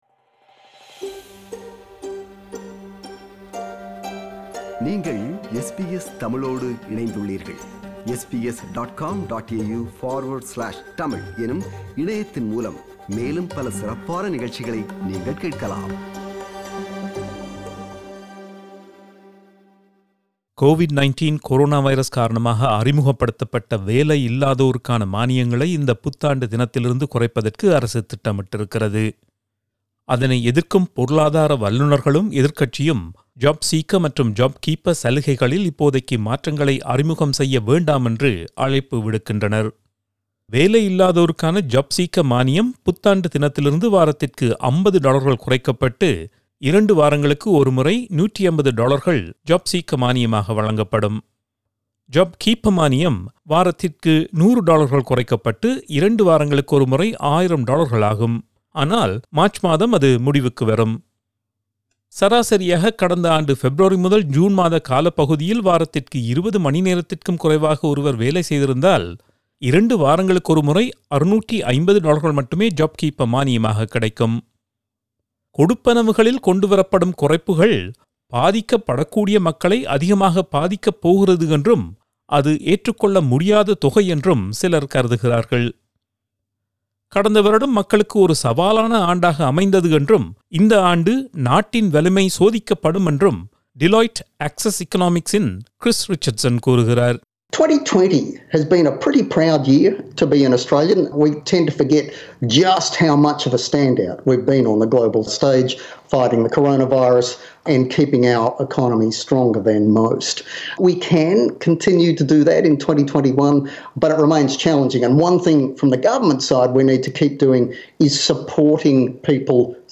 reports in Tamil